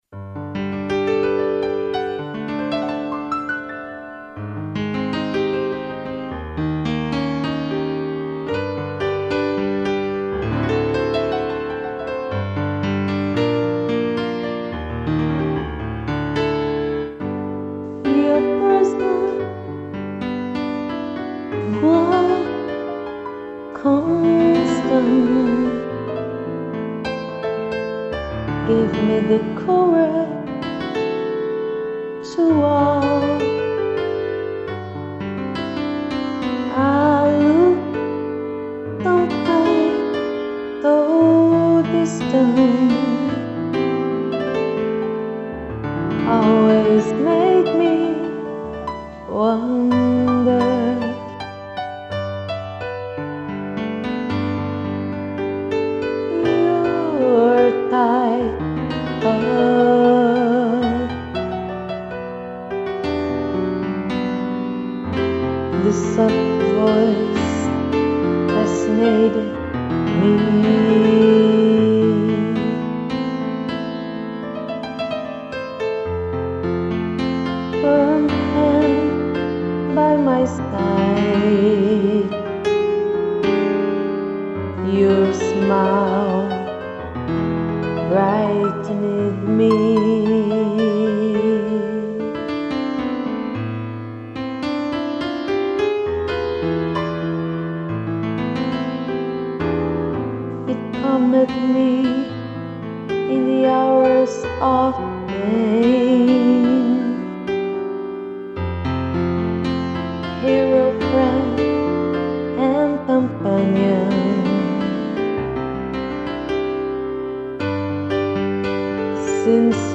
voz
piano